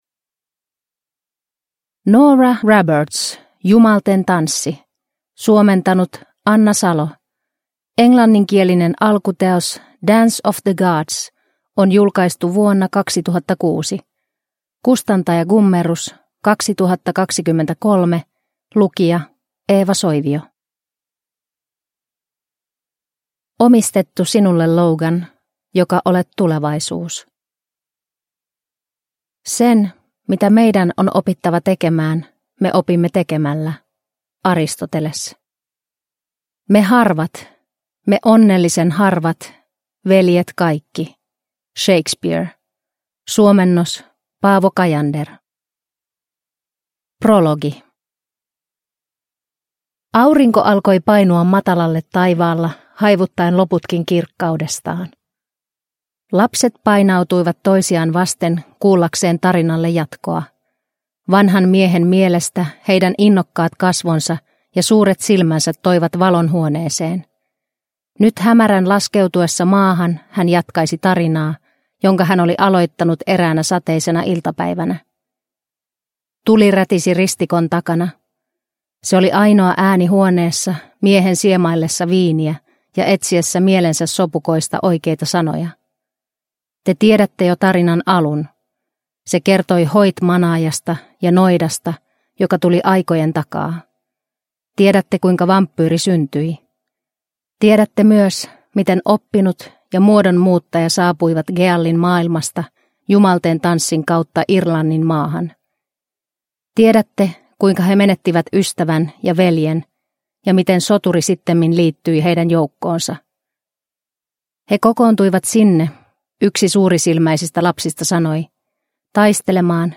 Jumalten tanssi – Ljudbok – Laddas ner